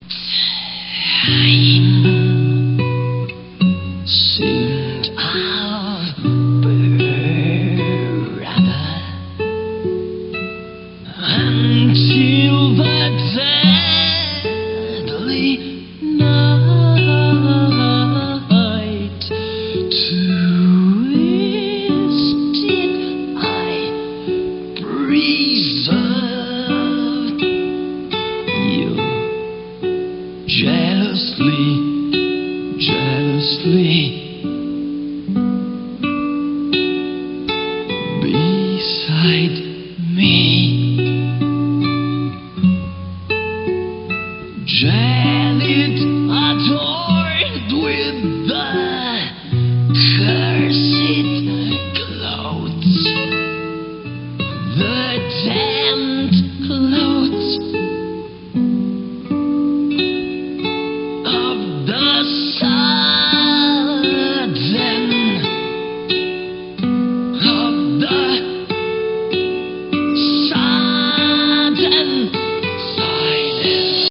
PIANO
DRUMS
VIOLIN
GUITAR
KEYBOARDS
PIPE ORGAN
CELLO
ACCORDION